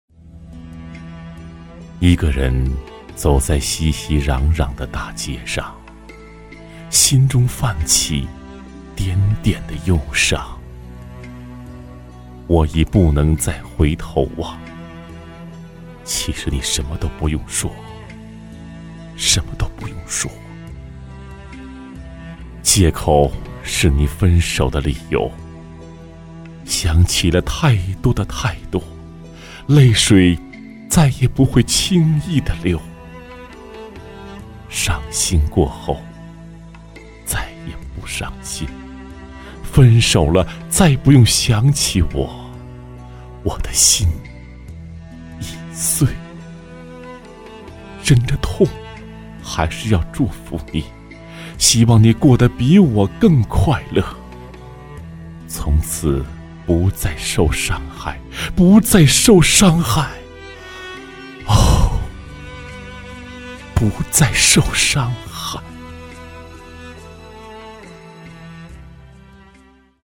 专题片/宣传片配音-纵声配音网
男22 爱别离（伤感低沉）.mp3